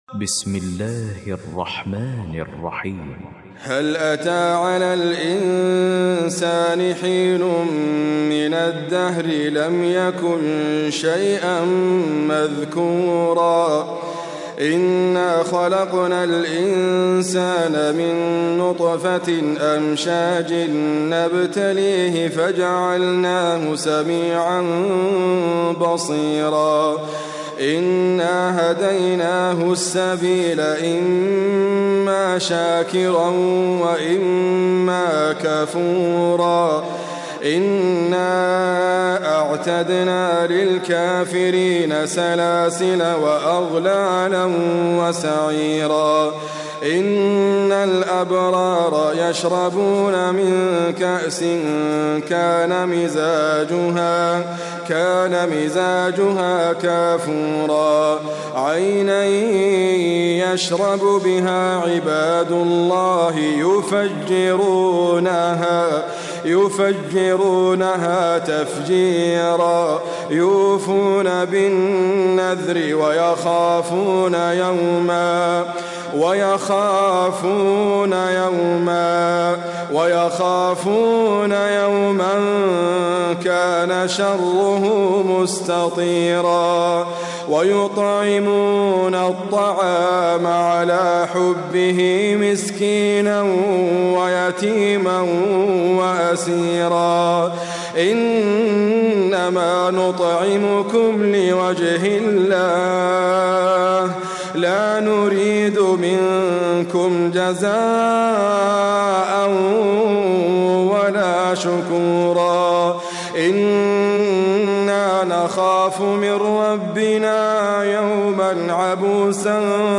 QuranicAudio is your source for high quality recitations of the Quran.
Surat Al-Insan by Idrees Abkar